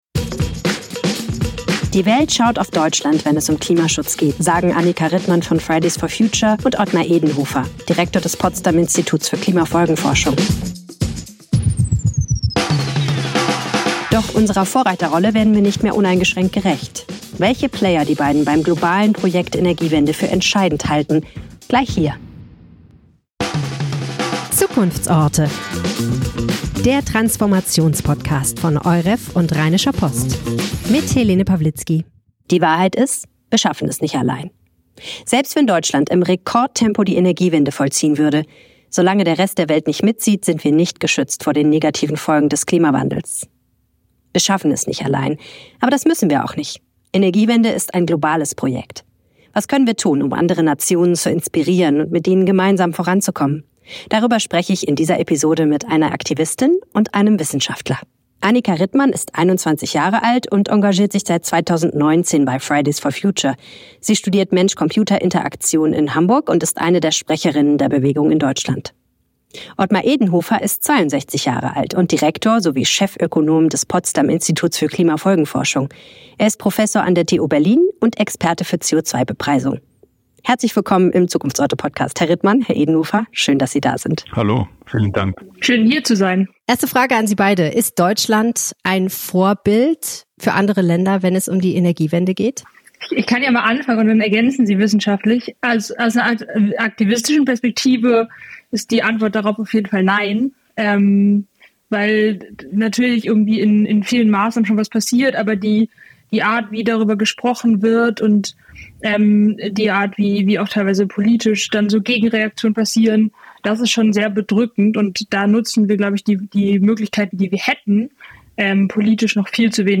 Ottmar Edenhofer im Interview